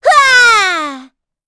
Gremory-Vox_Attack4.wav